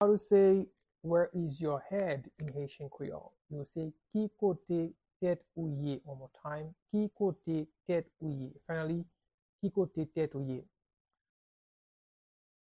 Pronunciation and Transcript:
How-to-say-Where-is-your-head-in-Haitian-Creole-–-Ki-kote-tet-ou-ye-pronunciation-by-a-Haitian-tutor.mp3